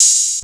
TS Open Hat 1.wav